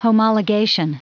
Prononciation du mot homologation en anglais (fichier audio)
Prononciation du mot : homologation